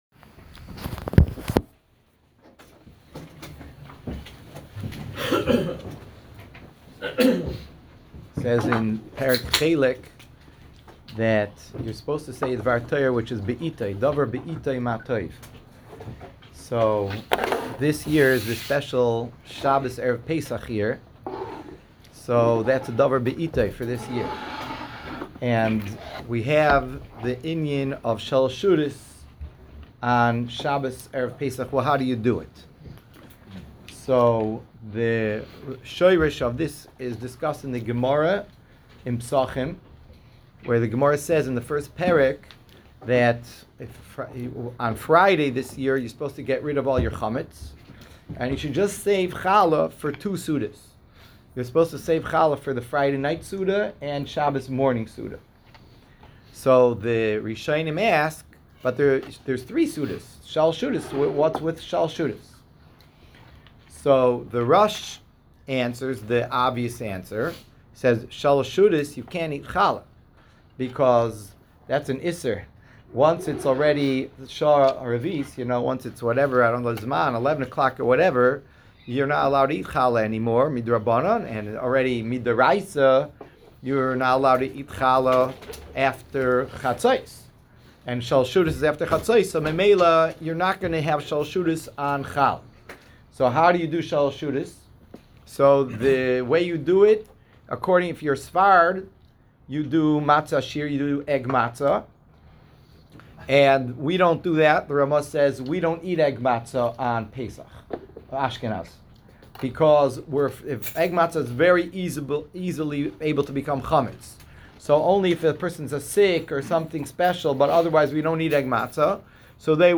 Pre-Pesach Lunch and Learn in Pikesville